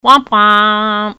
Play, download and share Womp Womp Frog original sound button!!!!
womp-womp-frog.mp3